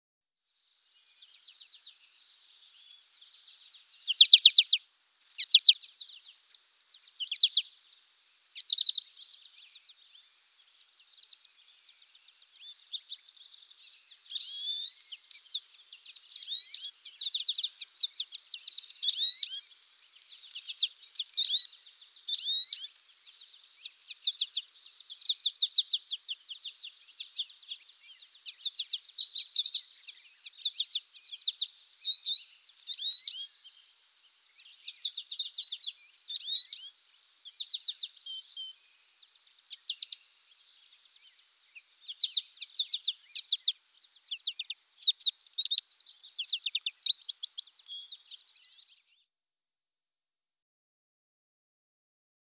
Goldfinch, American Chirps. A Goldfinch Chirps In The Foreground With Other Birds N The Background.